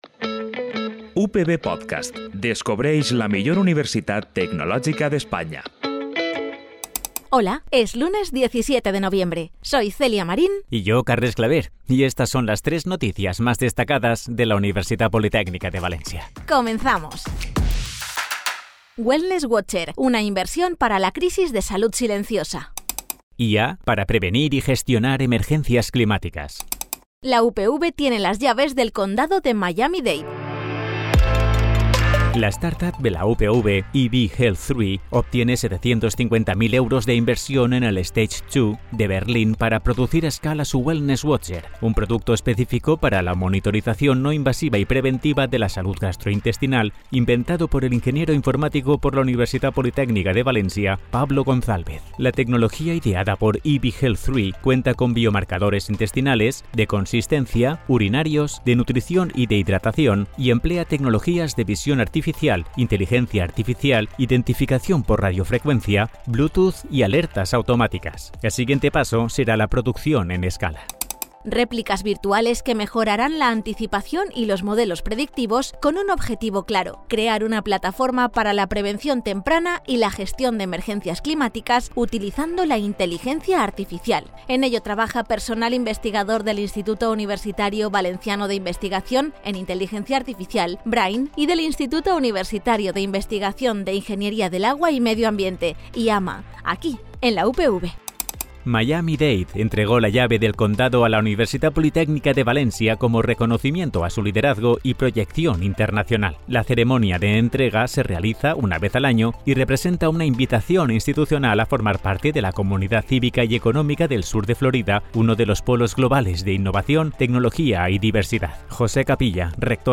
Es la versión sonora del Boletín Informativo para informarte de lo que pasa en la Universitat Politècnica de València.